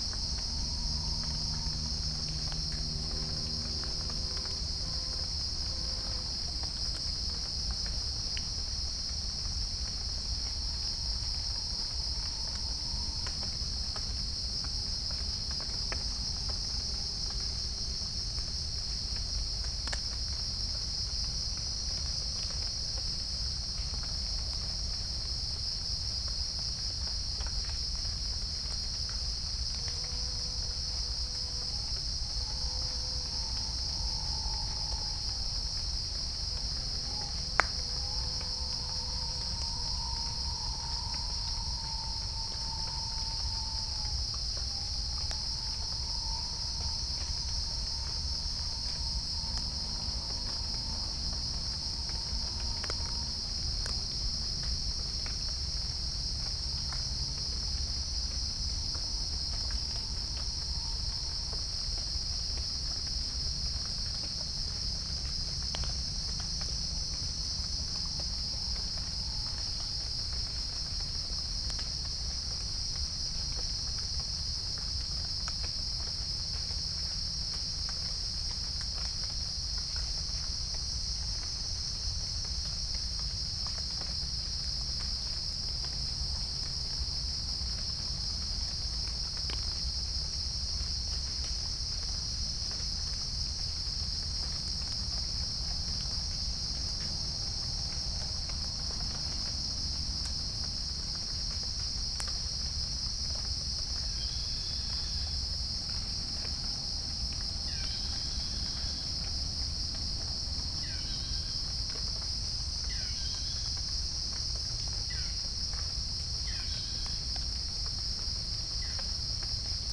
Geopelia striata
Pycnonotus goiavier
Halcyon smyrnensis
Orthotomus ruficeps
Dicaeum trigonostigma